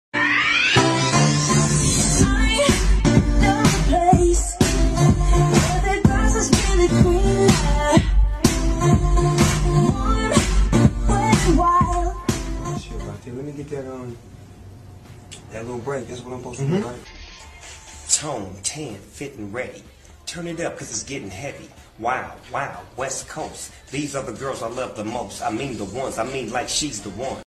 in the studio and recording his verse for the first time